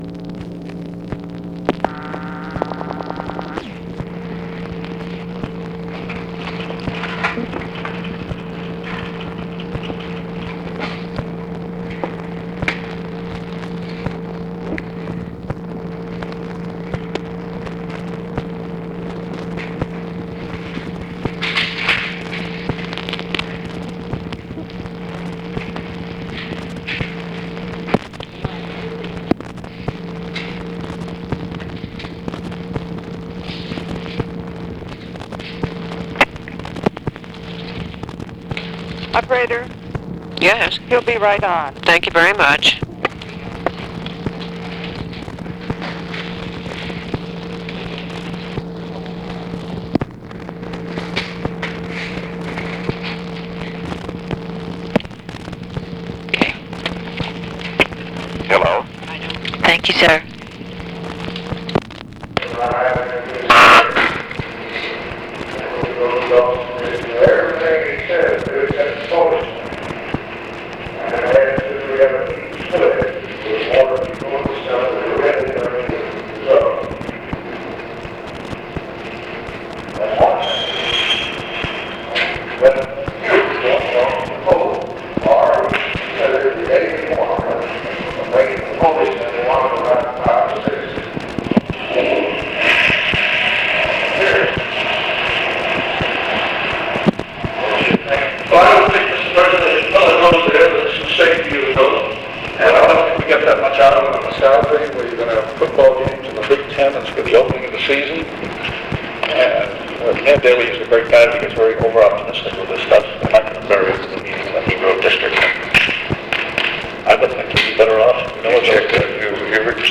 Conversation with ROBERT MCNAMARA and OFFICE CONVERSATION, September 10, 1964
Secret White House Tapes